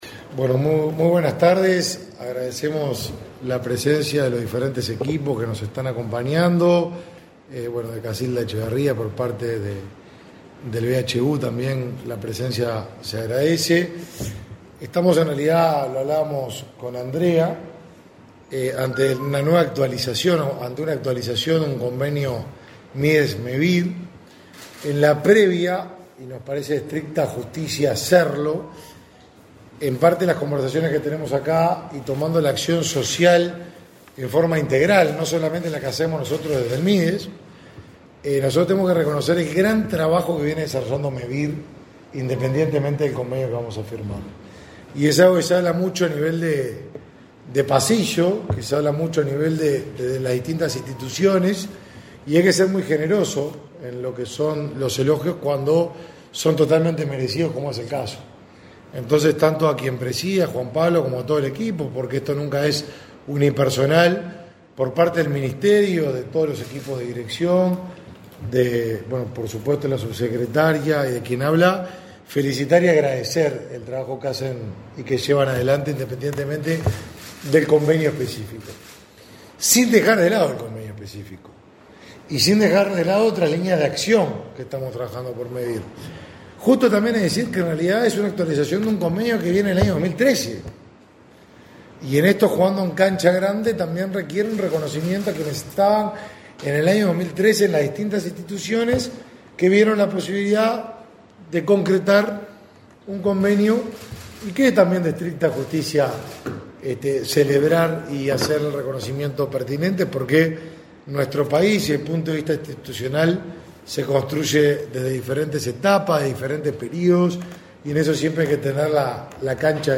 Conferencia de prensa por firma de convenio entre el Mides y Mevir